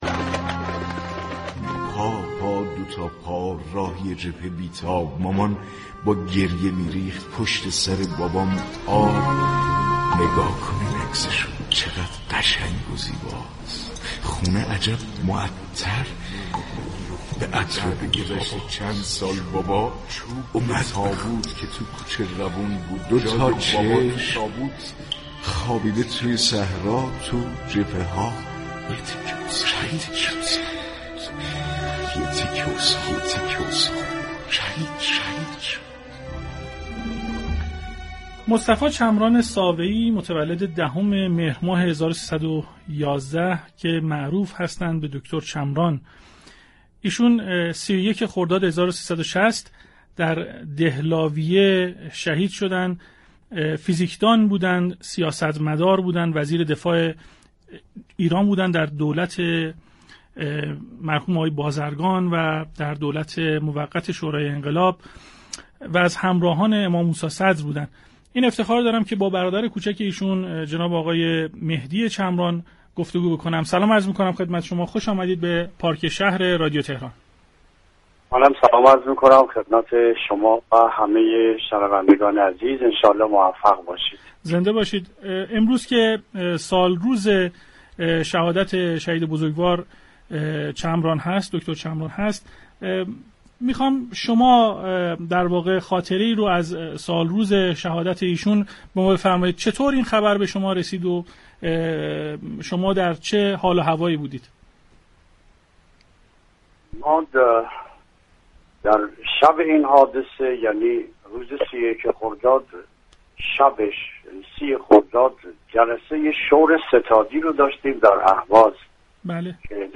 مهدی چمران، سیاستمدار اصولگرا، همزمان با فرارسیدن سالروز شهادت دكتر مصطفی چمران، از نحوه‌ی شهادت برادرش خاطراتی را برای مخاطبان